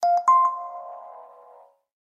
TaDa.ogg